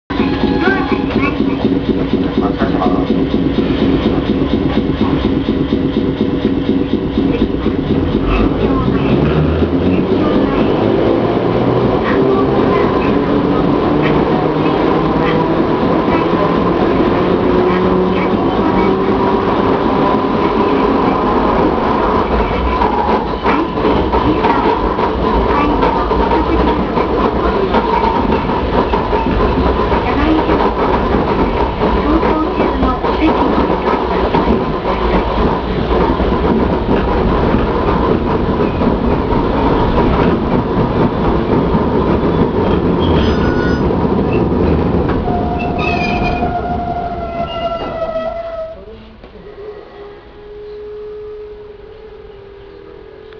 ・50形走行音
【城南線】大街道→県庁前@（53秒：291KB）…78号にて
一応前・中・後期で分けたのですが基本的に音は同じで、全て吊り掛け式。個人的には、数ある路面電車の中でもかなり派手な音を出す部類に感じました。